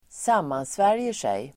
Uttal: [²s'am:ansvär:jer_sej]